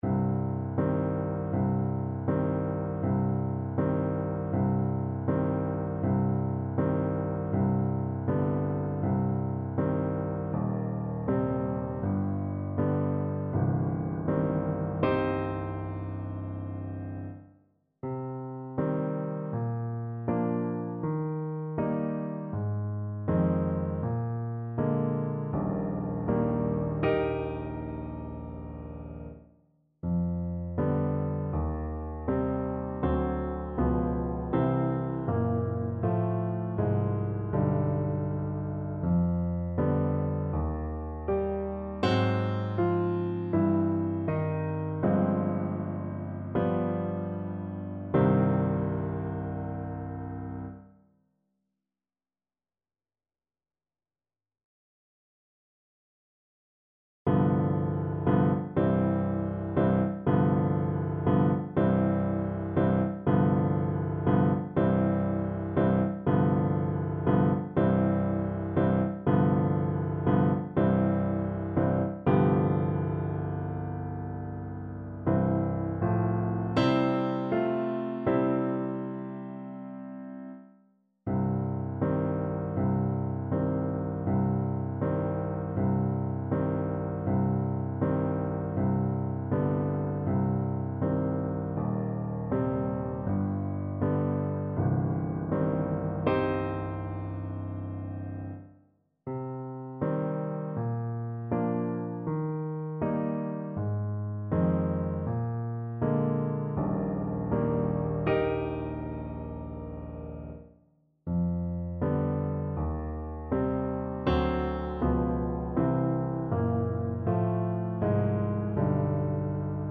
• Unlimited playalong tracks
4/4 (View more 4/4 Music)
Un poco andante
Classical (View more Classical Trombone Music)